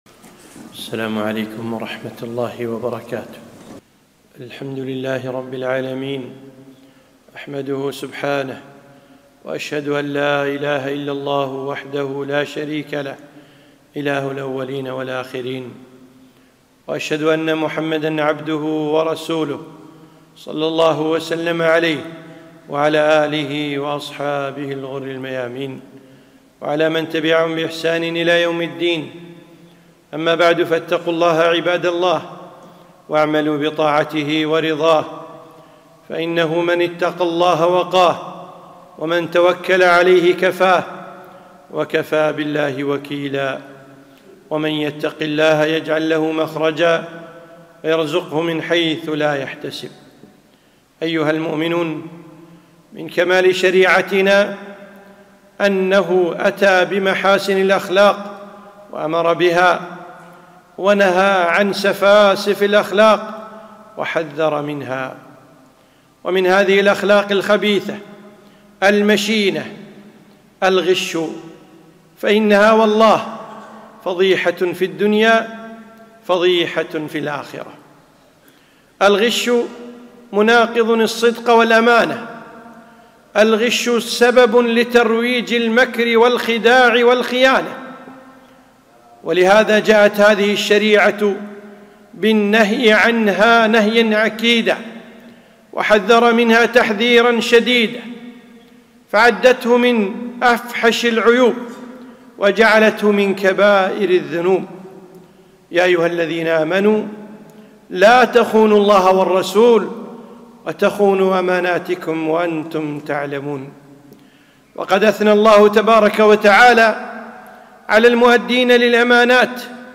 خطبة - الحذر من الغش